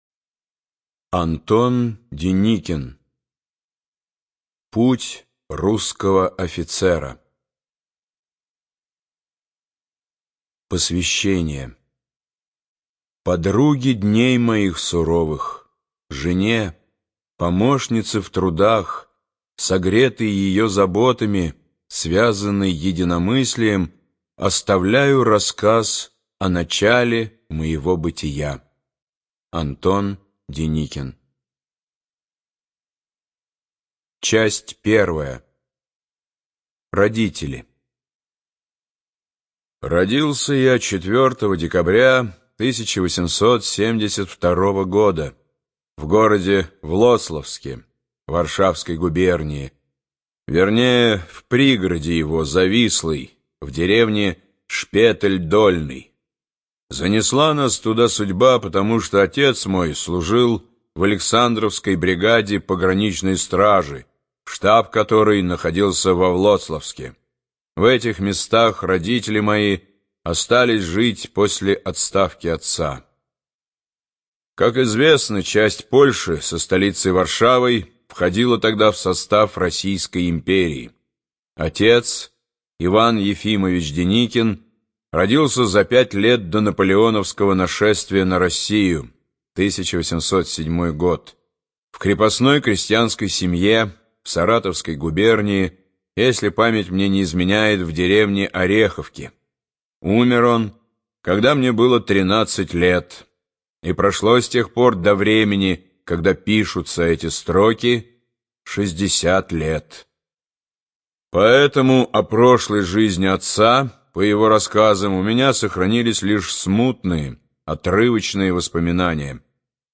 Студия звукозаписиБиблиофоника